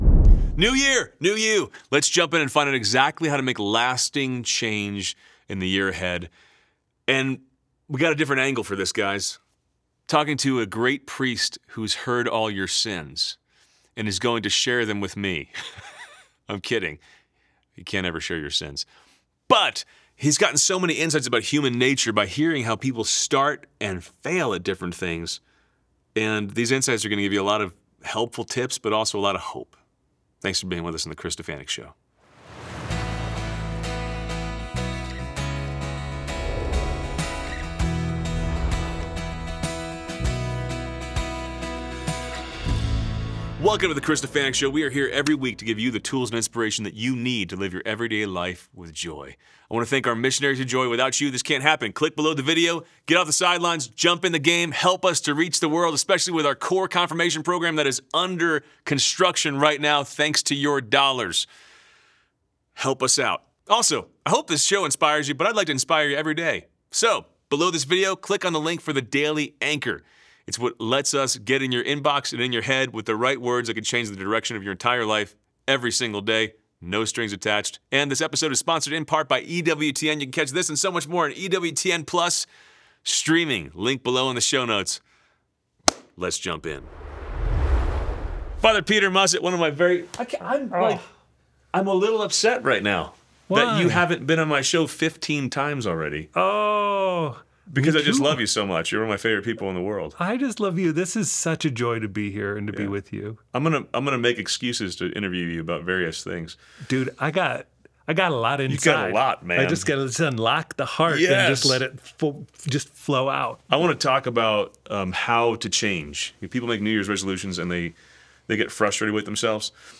In this conversation, we talk about real sins and real solutions so you can make real changes in your life.